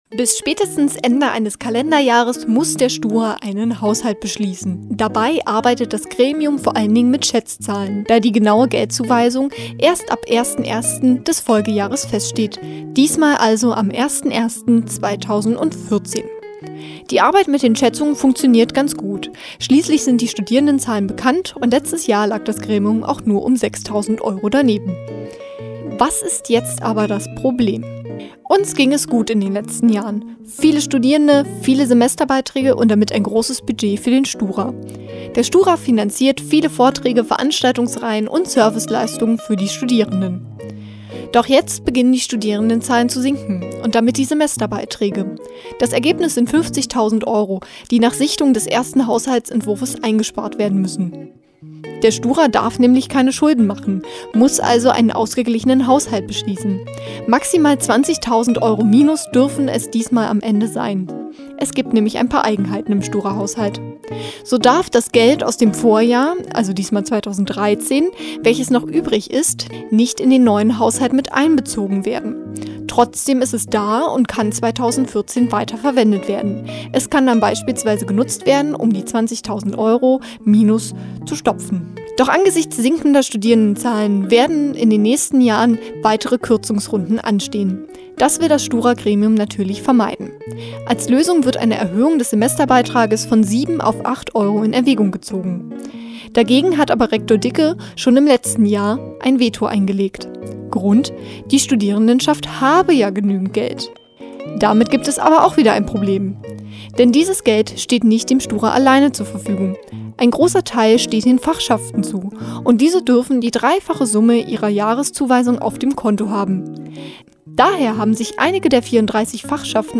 Ein kleiner Informationsbeitrag: